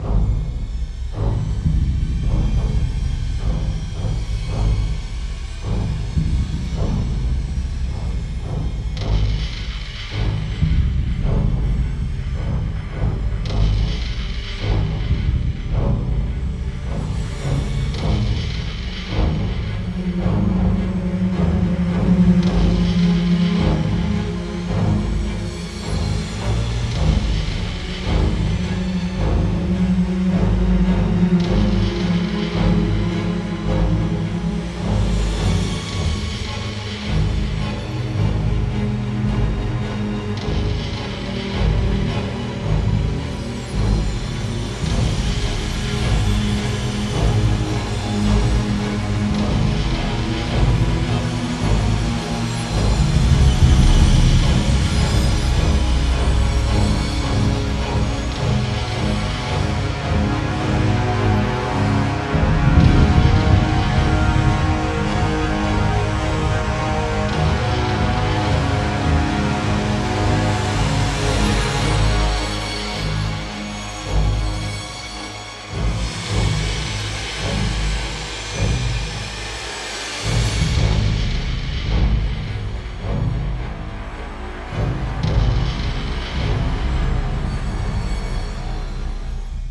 06 - Suspense